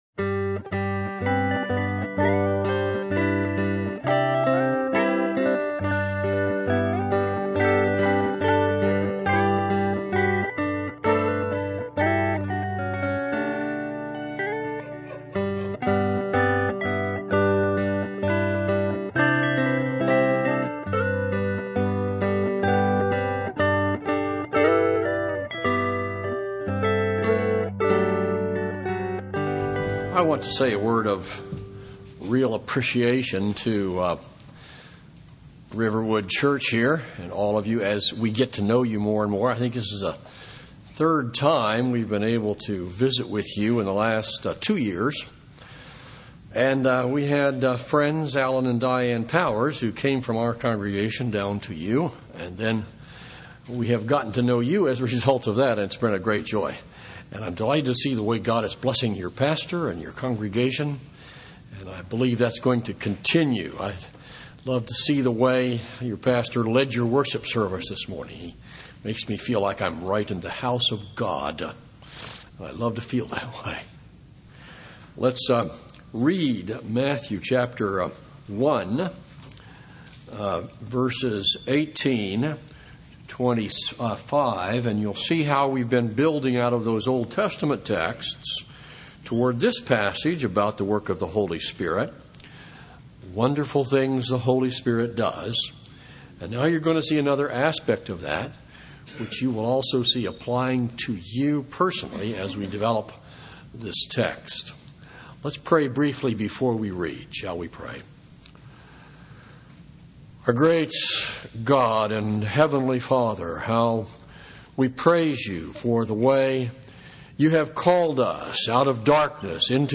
Sermon on Matthew 1:18-25 from February 3